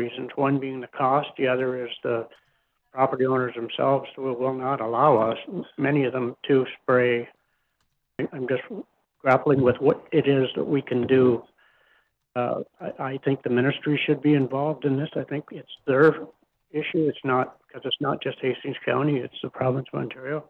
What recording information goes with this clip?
Hastings County Planning and Development Committee meeting, April 19, 2022 (Screengrab)